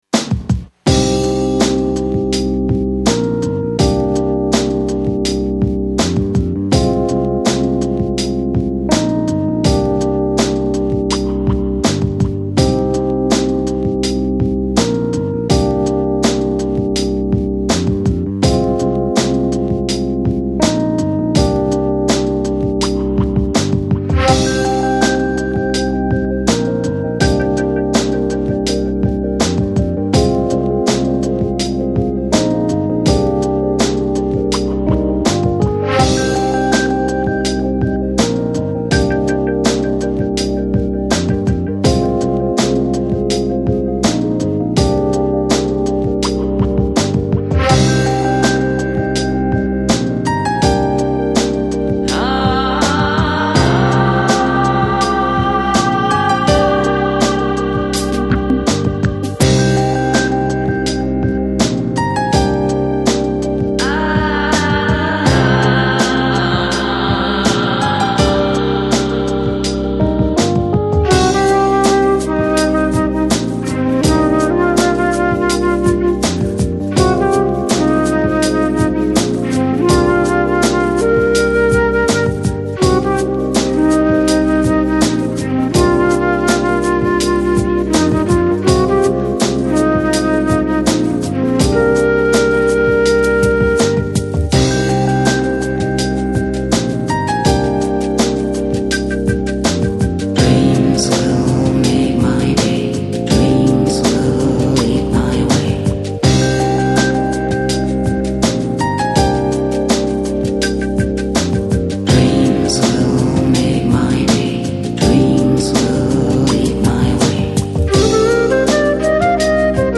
Жанр: Chill Out, Lounge, Downtempo